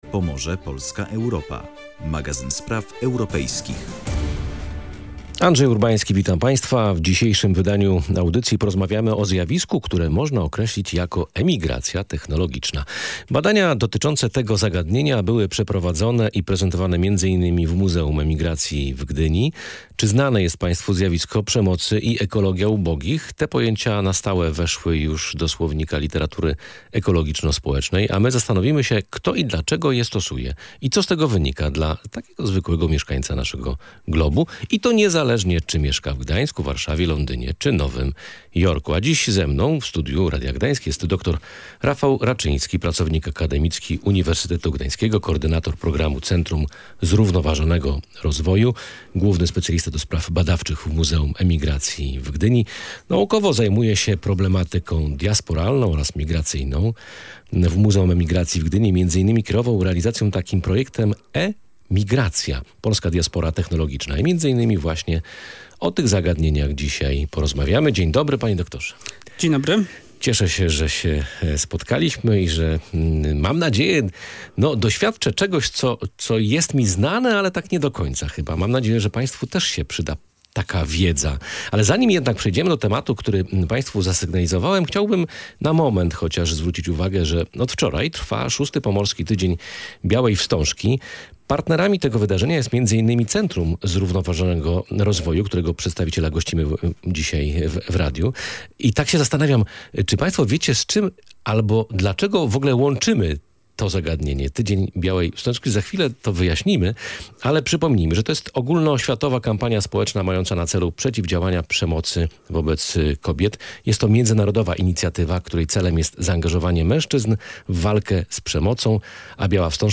W audycji „Pomorze, Polska, Europa” rozmawialiśmy o zjawisku, które można nazwać emigracją technologiczną. Zastanowiliśmy się, kto i dlaczego stosuje pojęcia takie jak przemoc oraz ekologia ubogich.